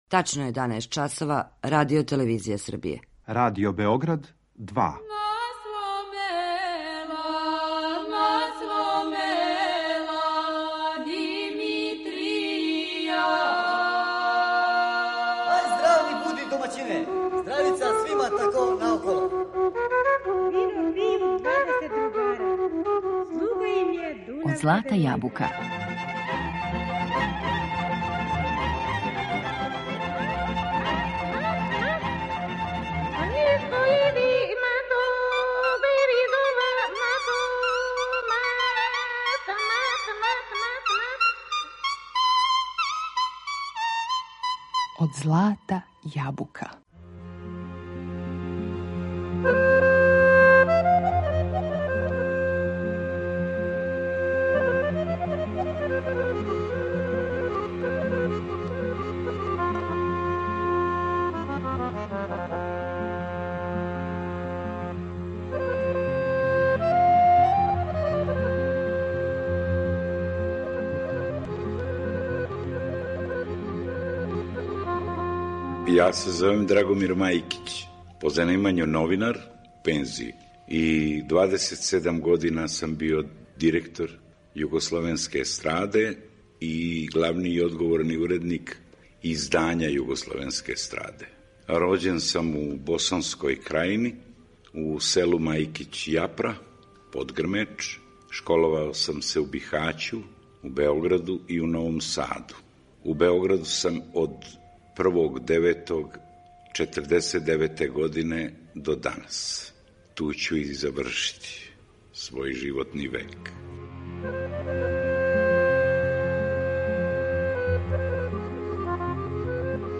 Емитујемо делове из интервјуа који је забележен у децембру 2004. године, уз песме које су остале трајно забележене у Радио Београду.